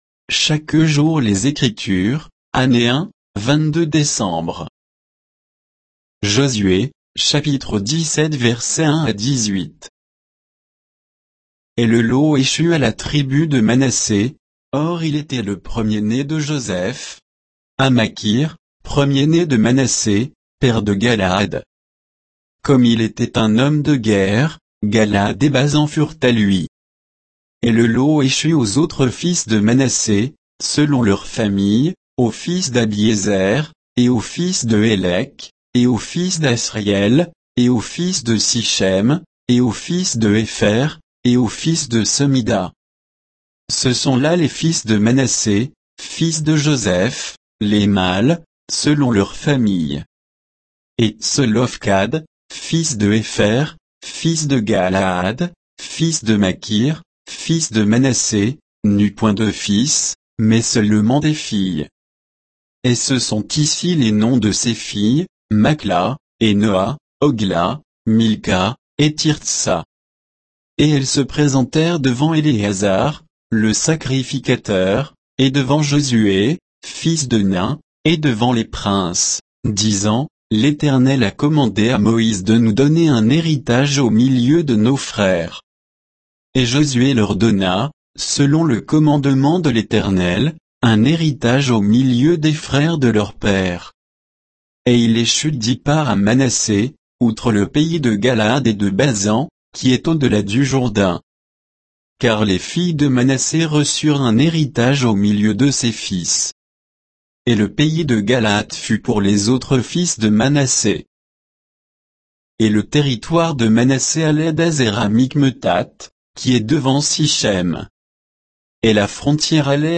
Méditation quoditienne de Chaque jour les Écritures sur Josué 17